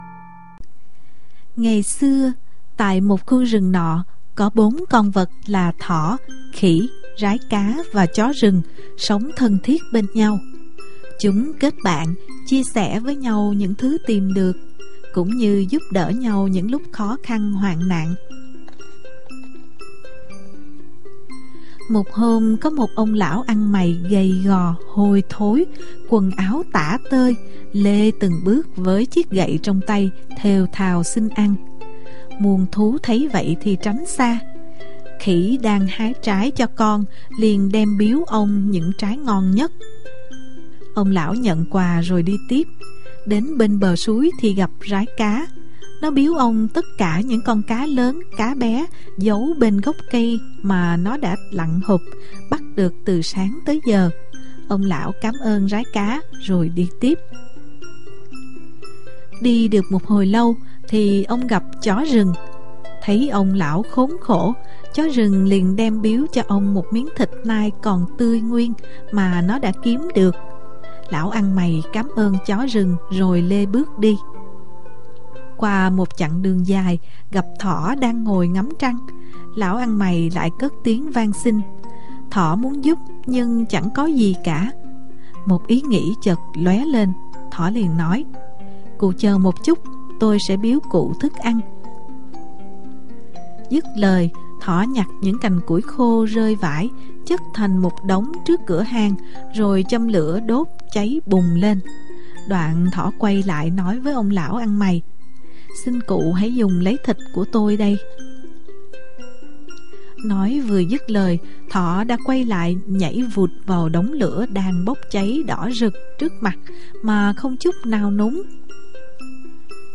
Sách nói | Sự tích con thỏ mặt trăng